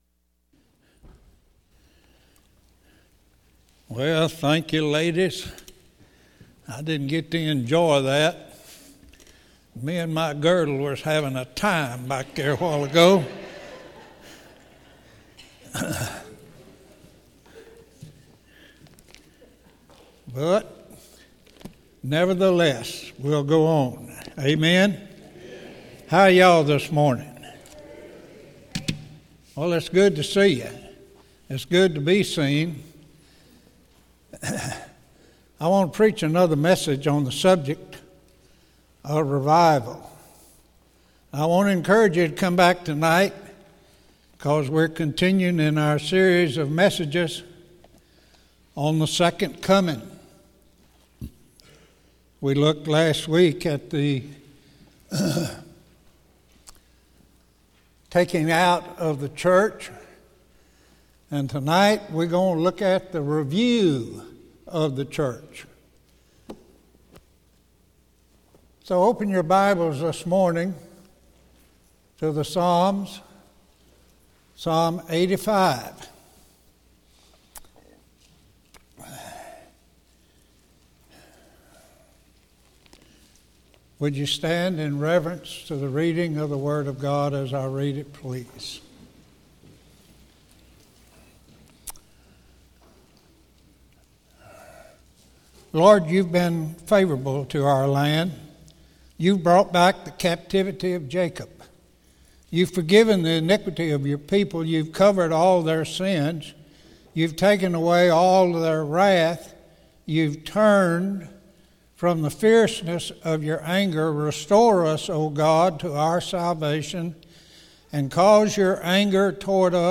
Morning Worship - Central Baptist Church